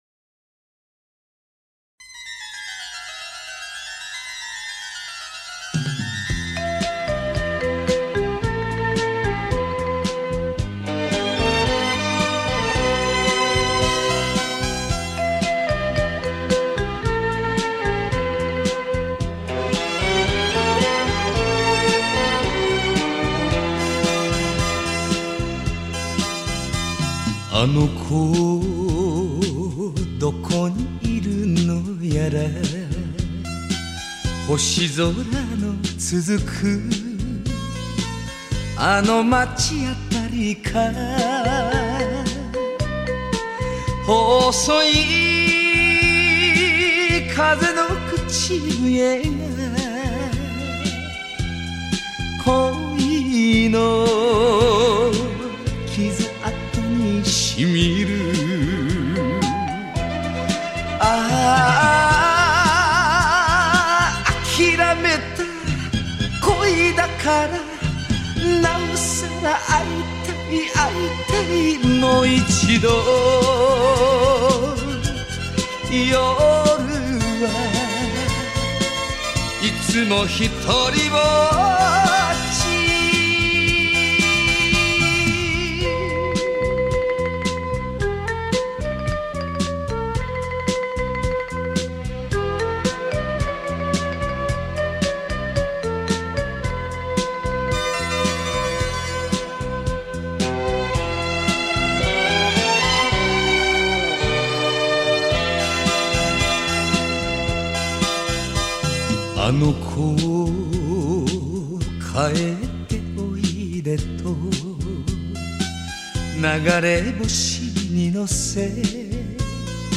收录日本演歌精选/曲曲动听 朗朗上口